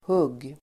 Uttal: [hug:]